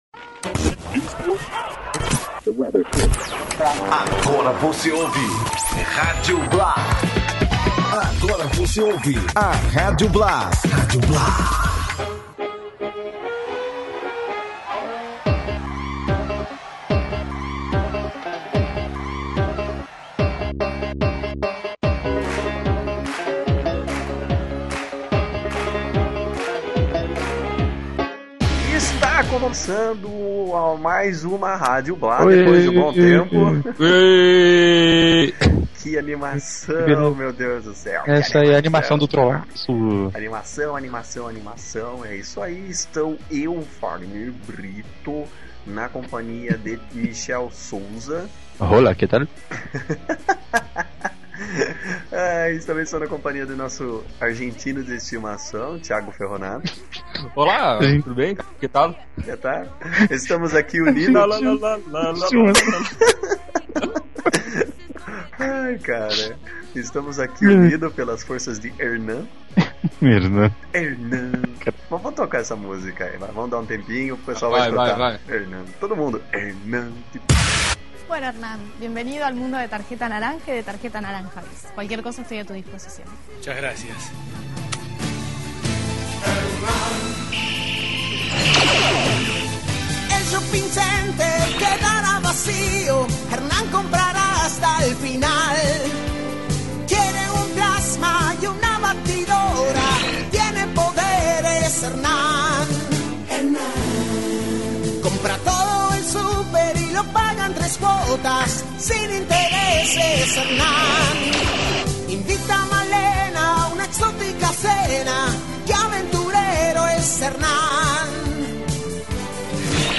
conversam sobre aniversários enquanto ouvem a setlist criada por nossos ouvintes através do twitter.